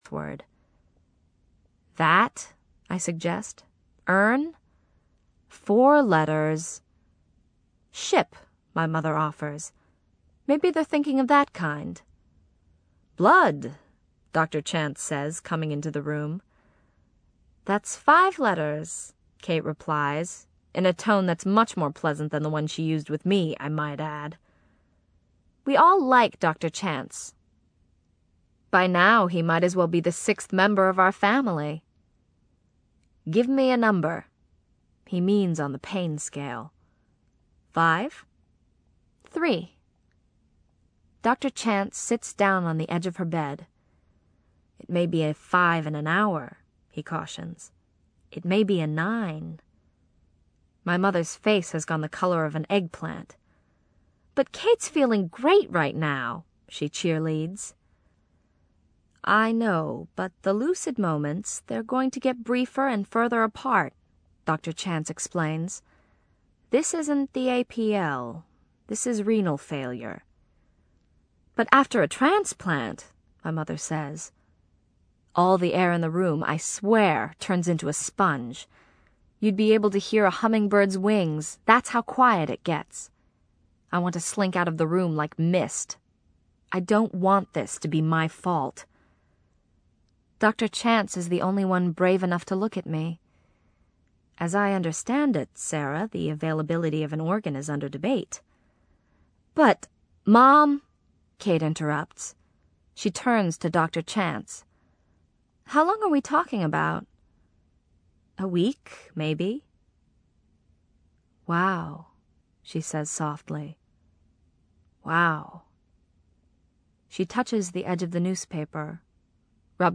英文广播剧在线听 My Sister's Keeper（姐姐的守护者）101 听力文件下载—在线英语听力室